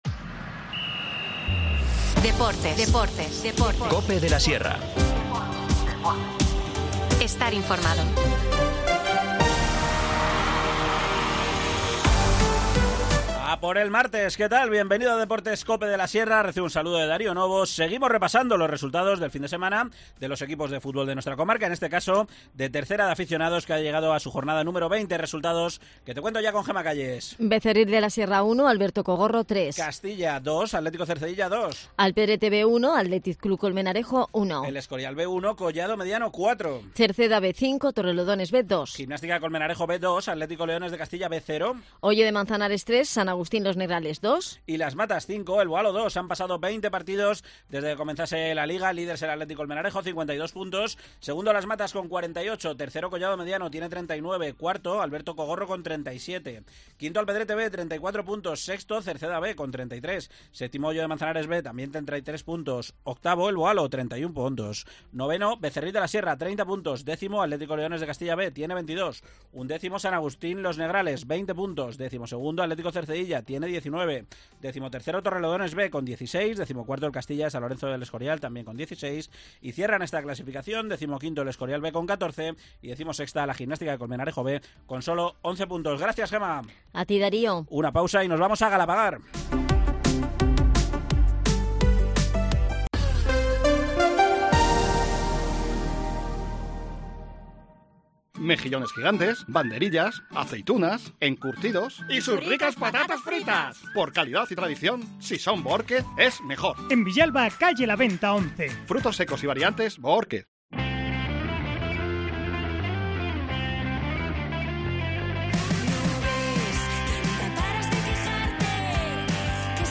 Deportes local
El concejal de Inclusión y Accesibilidad, José María Escudero, nos cuenta los detalles de este fin de semana en el Velódromo Tasio Greciano.